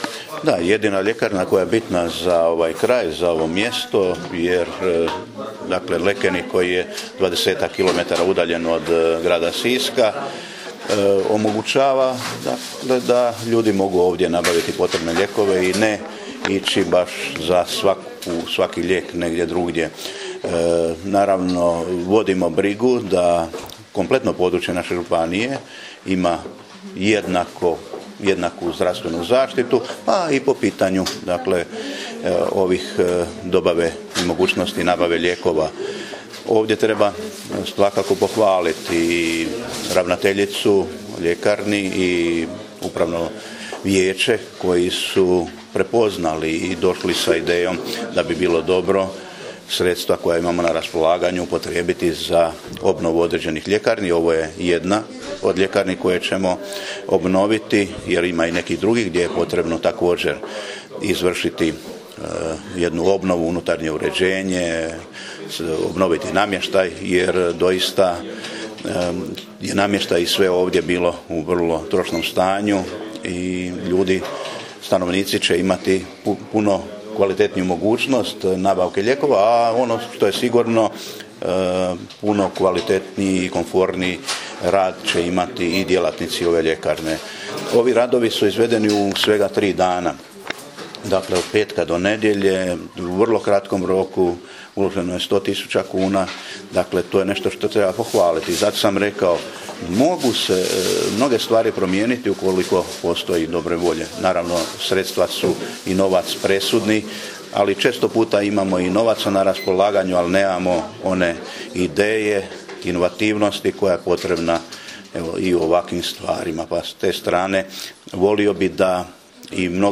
Izjavu župana u ovoj prigodi poslušajte ovdje: